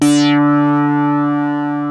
OSCAR 8 D#4.wav